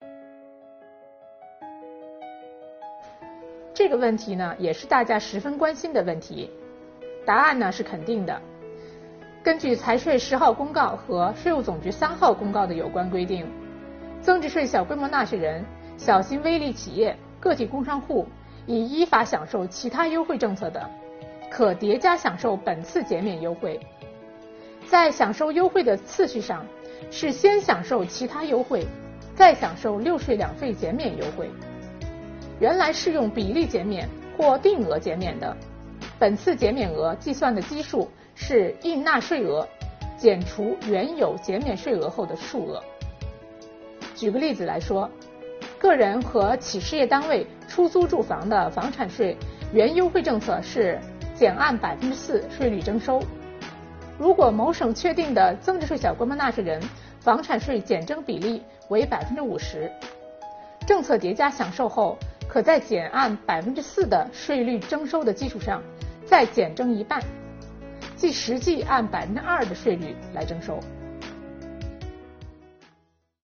本期课程由国家税务总局财产和行为税司副司长刘宜担任主讲人，解读小微企业“六税两费”减免政策。今天，我们一起学习：已享受其他优惠政策的纳税人可以叠加享受“六税两费”减免优惠吗？